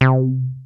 ACID L WET 1.wav